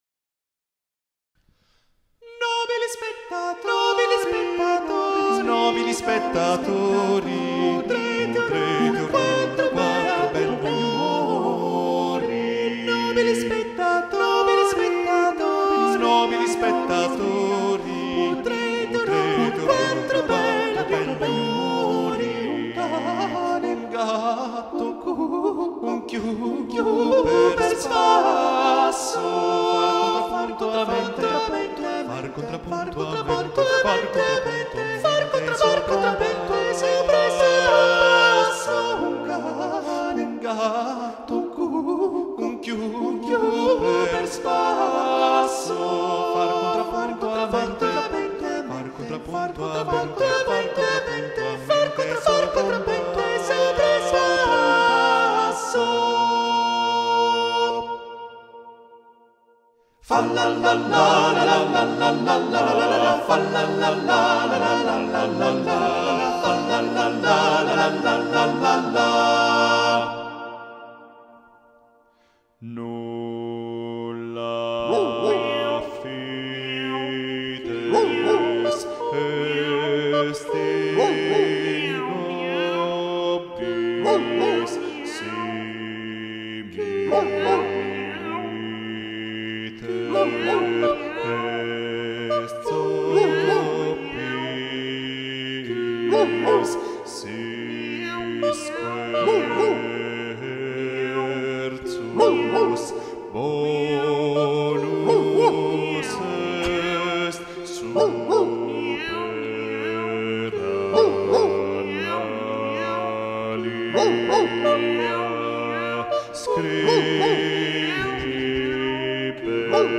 Madrigale
Chor Melodie und Text: Adriano Branchieri (1567 - 1634)